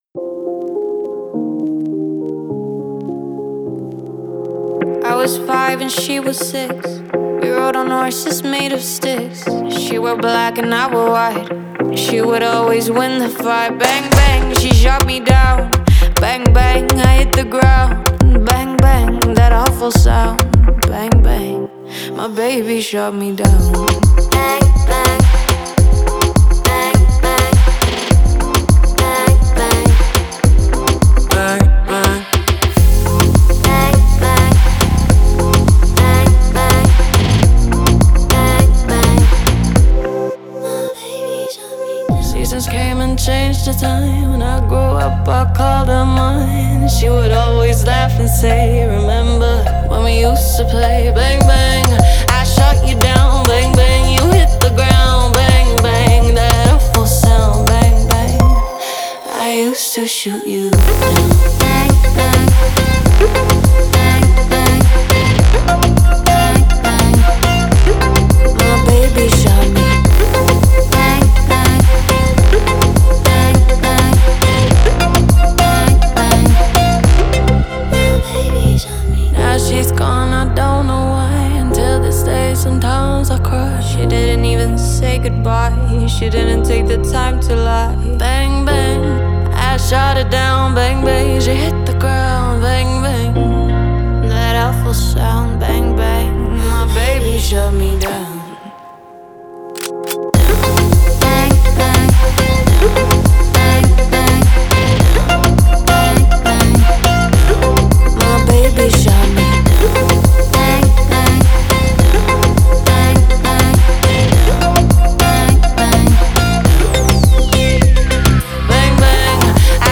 это энергичная электронная танцевальная композиция